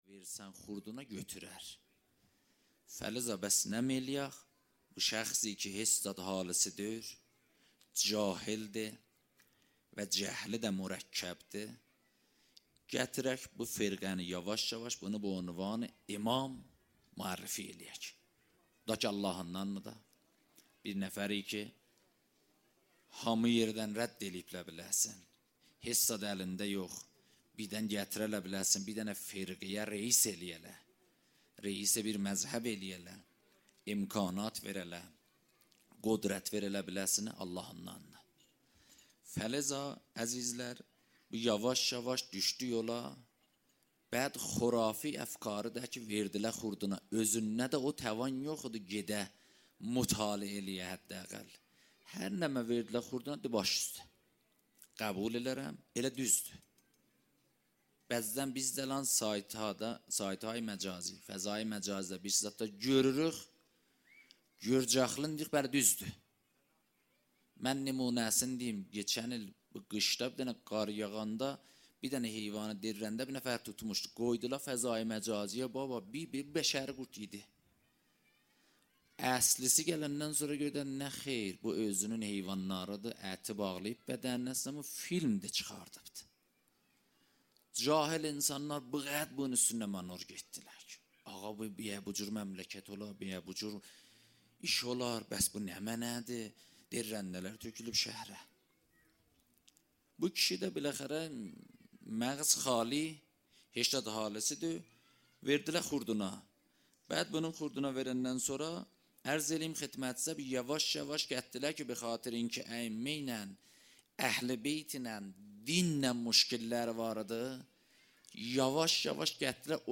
0 0 سخنرانی